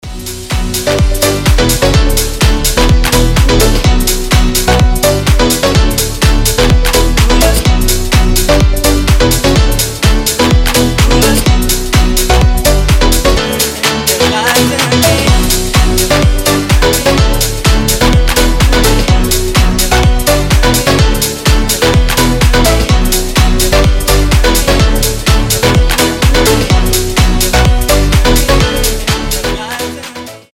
• Качество: 320, Stereo
громкие
remix
зажигательные
EDM
энергичные
Стиль: future house